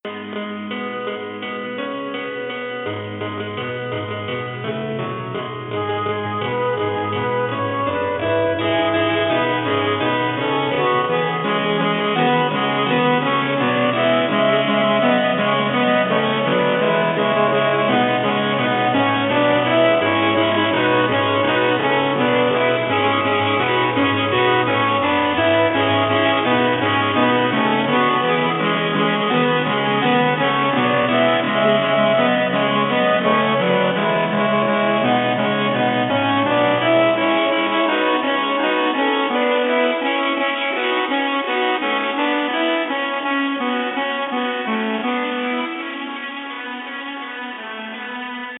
6 part instrumental version to demonstrate the next step (6 vocal parts was
Spring Round 6 part instrumental.mp3